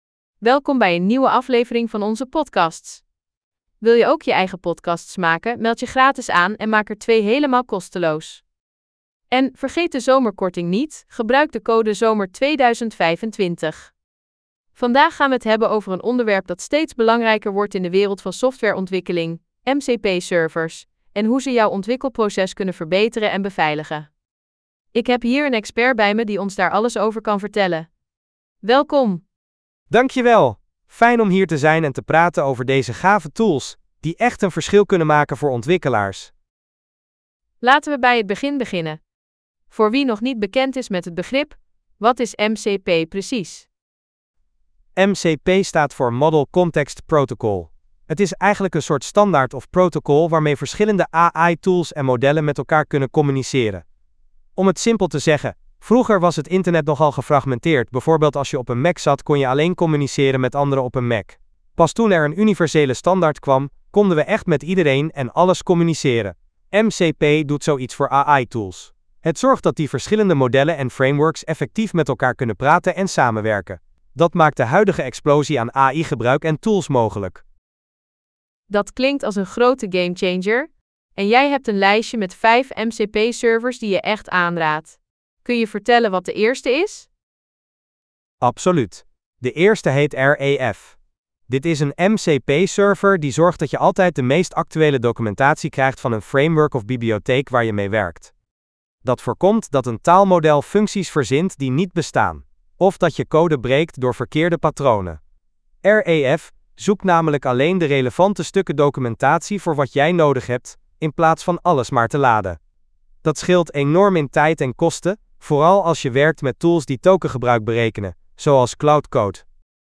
Podcast gegenereerd van geüploade script: MCP-servers zijn de missing link tussen AI-tools en je dev-workflow